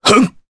Crow-Vox_Jump_jp.wav